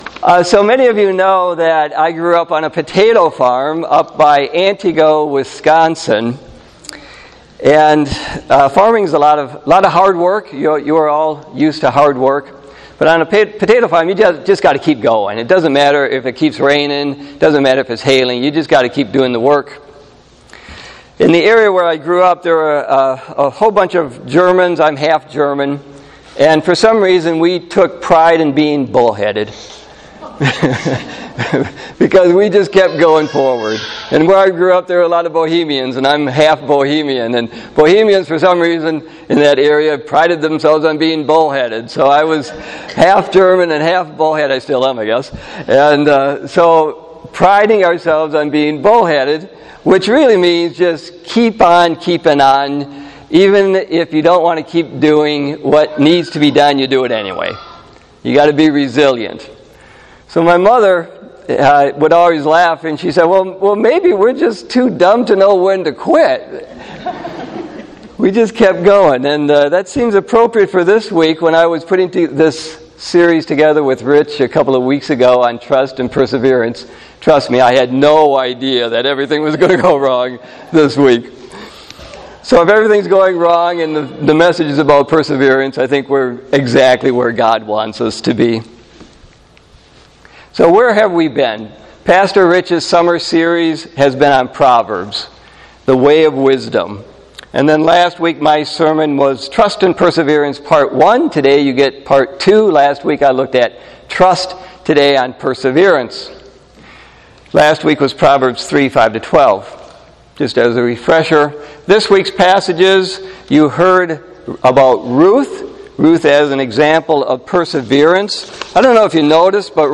Sunday-8-26-18-Worship-Service.mp3